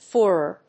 /ˈfjʊərə(英国英語), ˈfʊrɝ(米国英語)/